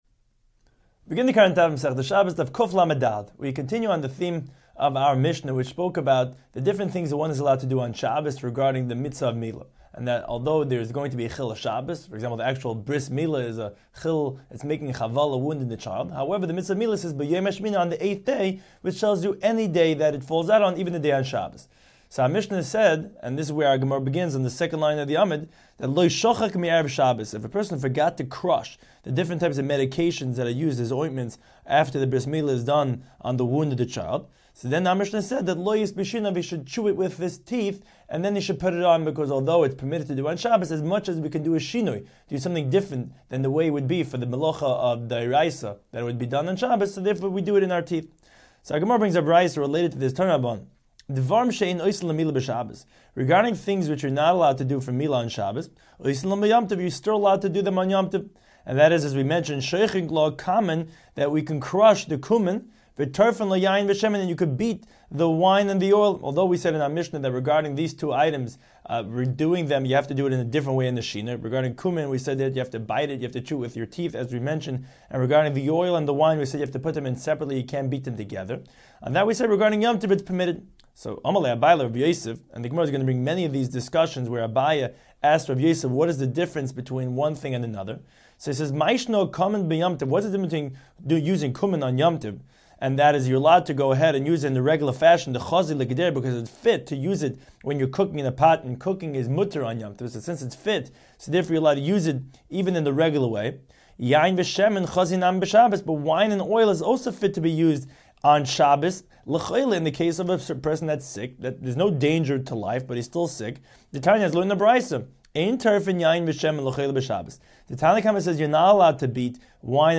Daf Hachaim Shiur for Shabbos 134